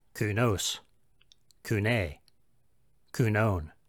This series of posts will focus on explaining how to pronounce the Koine Greek alphabet and words using a Reconstructed Koine Pronunciation.